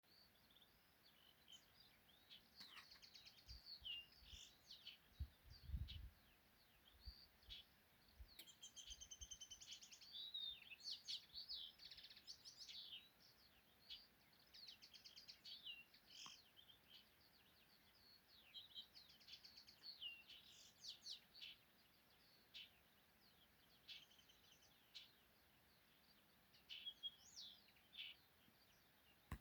Kaņepītis, Linaria cannabina
Administratīvā teritorijaDaugavpils novads
StatussDzied ligzdošanai piemērotā biotopā (D)